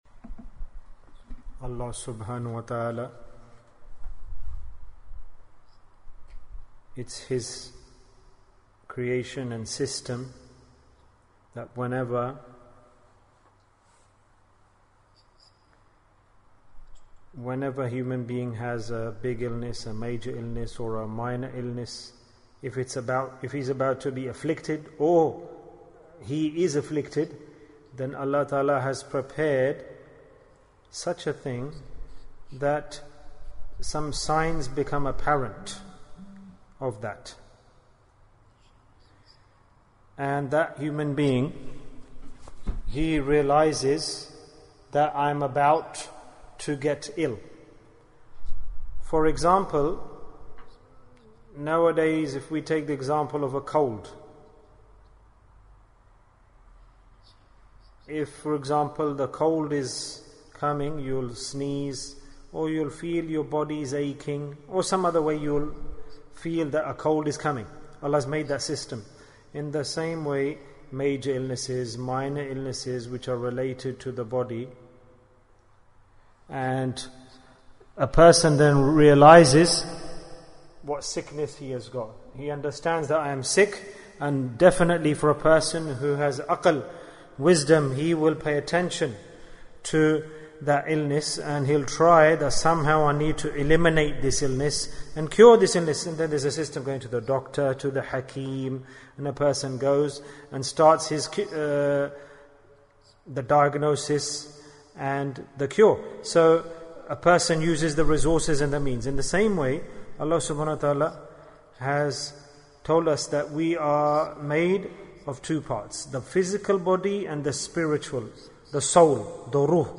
Symptoms of Heart Disease Bayan, 33 minutes13th August, 2020